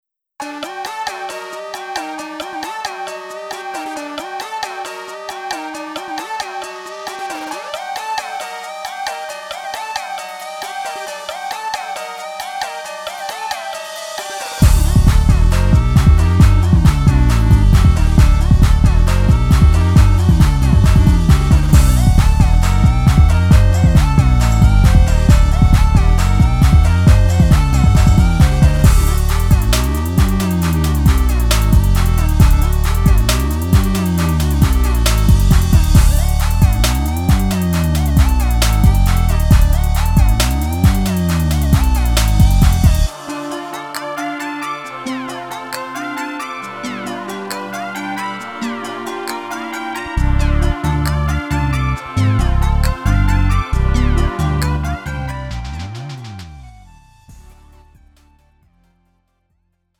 음정 -1키 3:39
장르 가요 구분 Lite MR
Lite MR은 저렴한 가격에 간단한 연습이나 취미용으로 활용할 수 있는 가벼운 반주입니다.